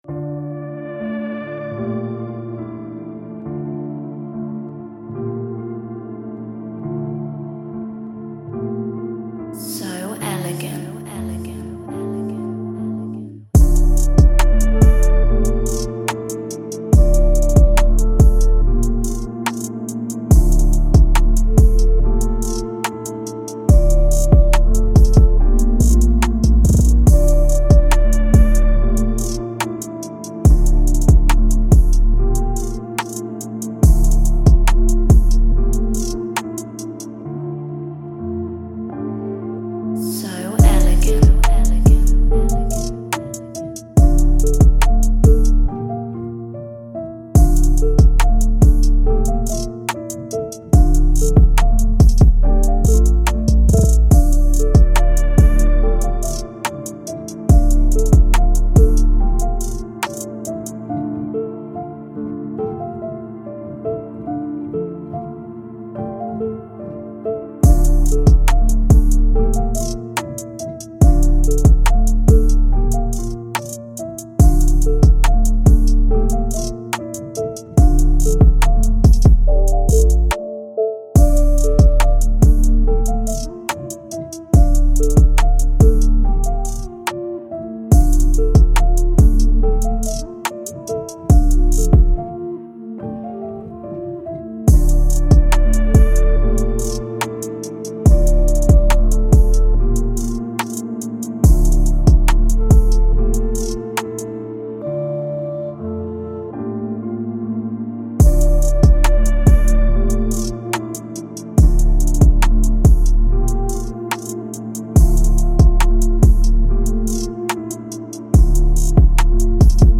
Hip-Hop , Trap